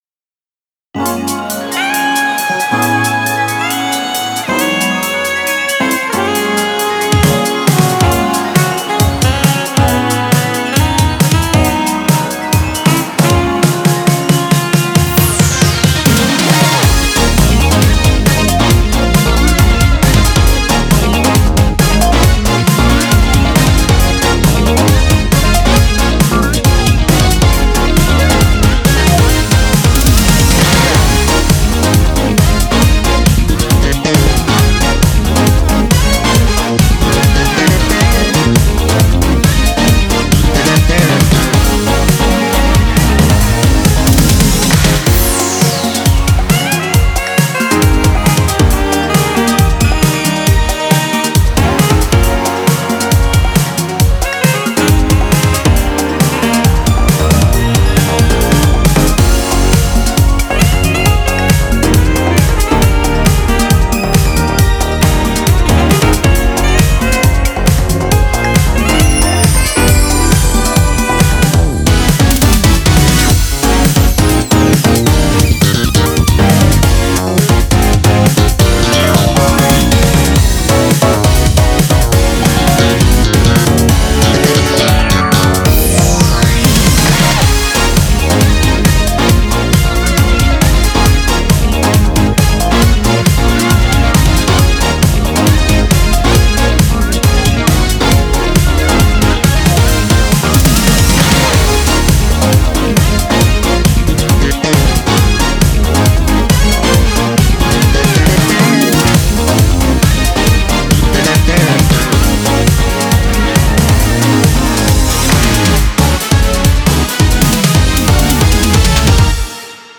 BPM136
Genre: 80's SYNTH FUNK.